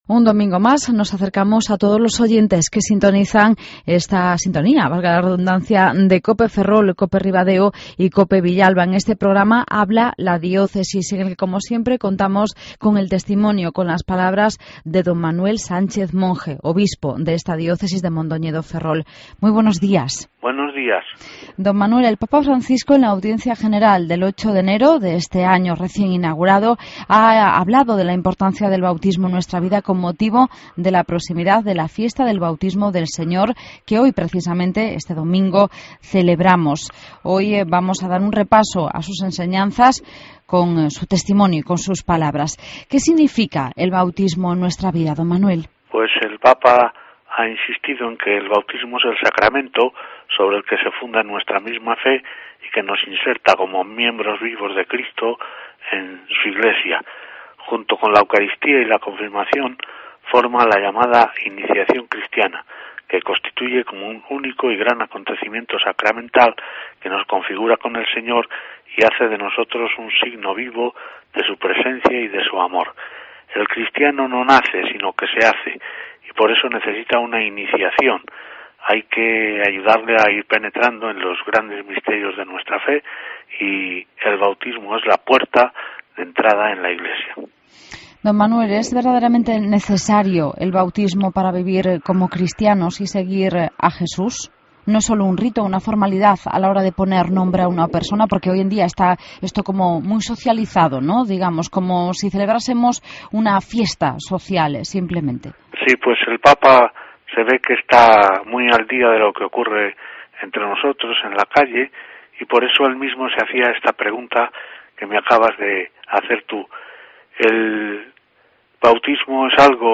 Redacción digital Madrid - Publicado el 13 ene 2014, 14:05 - Actualizado 02 feb 2023, 00:07 1 min lectura Descargar Facebook Twitter Whatsapp Telegram Enviar por email Copiar enlace El obispo de la Diócesis de Mondoñedo-Ferrol, don Manuel Sánchez Monge, habla de la importancia del bautismo en nuestra vida.